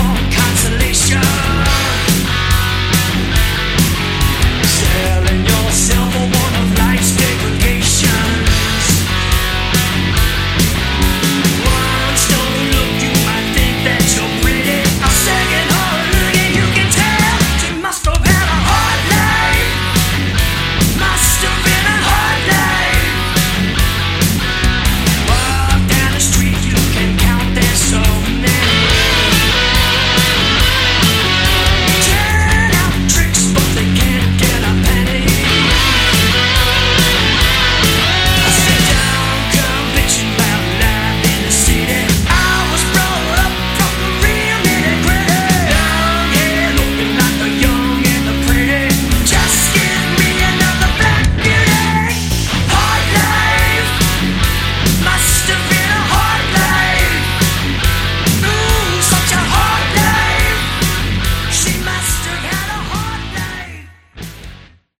Category: Hard Rock
Vocals, Guitar
Guitar, Backing Vocals
Bass, Backing Vocals
Drums, Backing Vocals